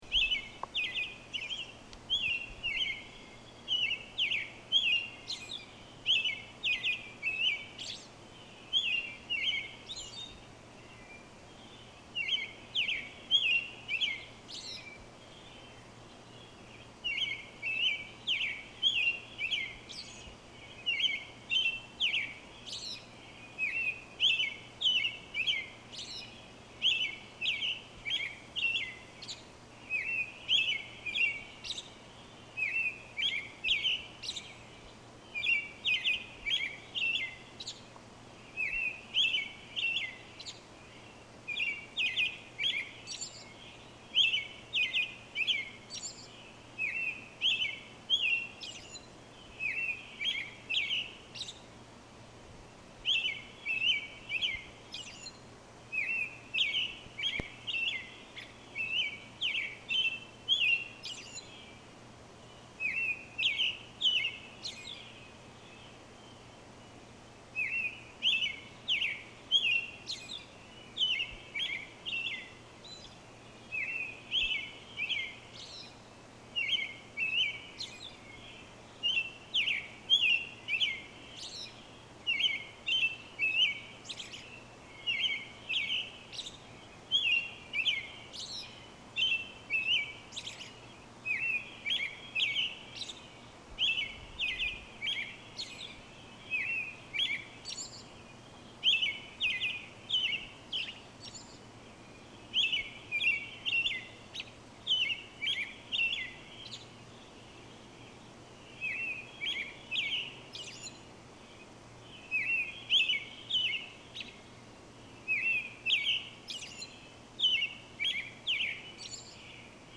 American Robin
They have a gentle call and a sociable nature, preferring to roam in flocks (almost herds at times), but will scold you if you get too close.